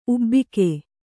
♪ ubbike